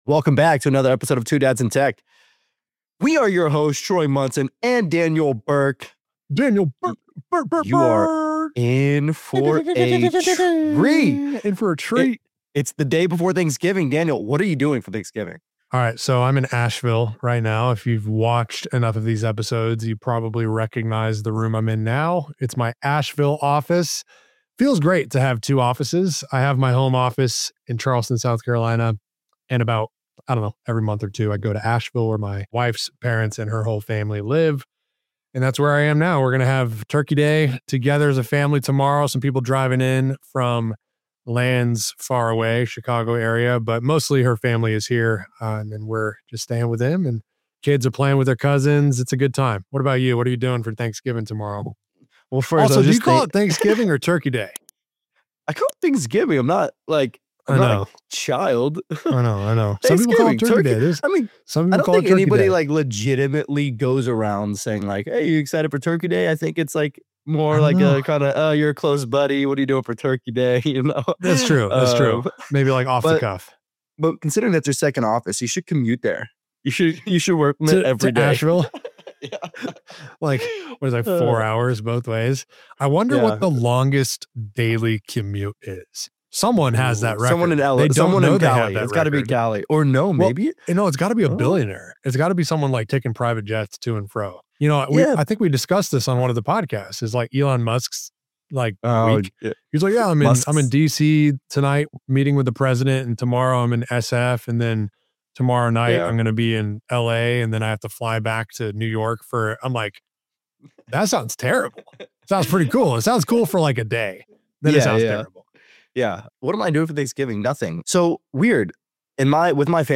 Welcome to episode 51 of Two Dads in Tech — packed with laughter, honesty, and the occasional deep thought between bites of turkey.